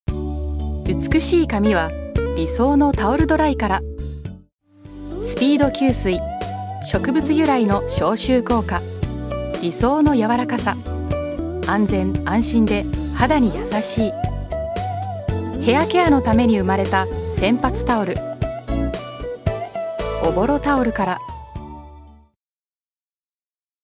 ナチュラルな英語発音は、館内案内などの英語バージョンにも
Voice sample 1